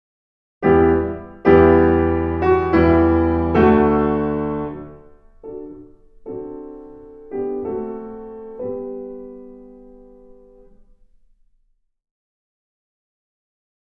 Piano
Dry:
selene-piano-dry.mp3